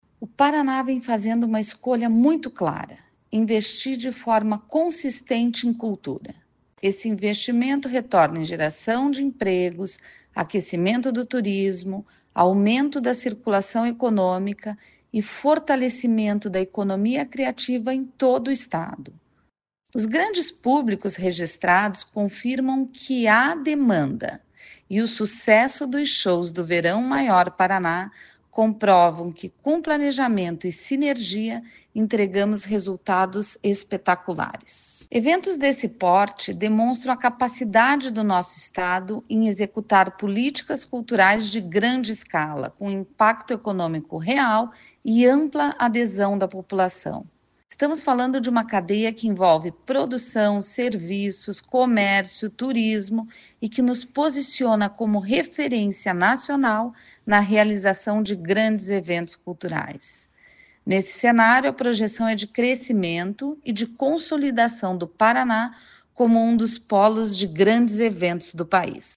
Sonora da secretária Estadual da Cultura, Luciana Casagrande, sobre o recorde de público do show do DJ Alok